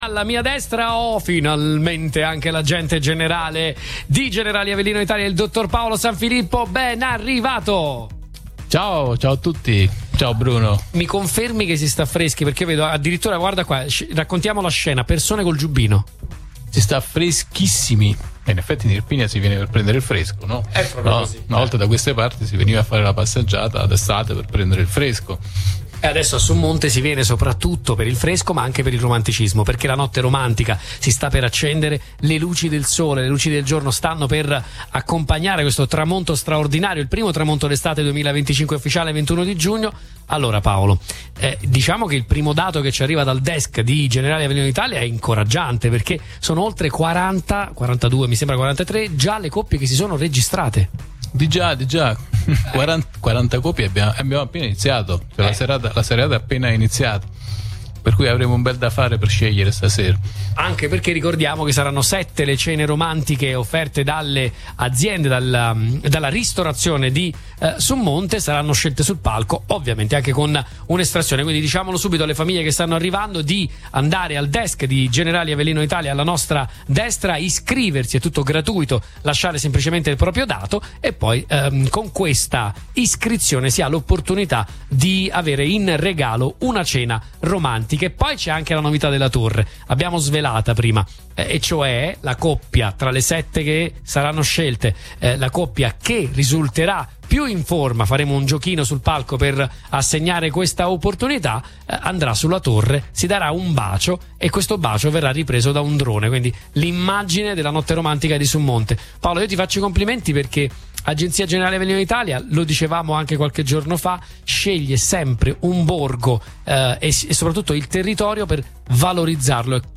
Sabato 21 giugno, in occasione del solstizio d’estate, il borgo di Summonte, tra i più belli d’Italia, ha ospitato una nuova edizione della Notte Romantica, evento patrocinato dal Comune di Summonte, in collaborazione con Generali Avellino Italia e Radio Punto Nuovo.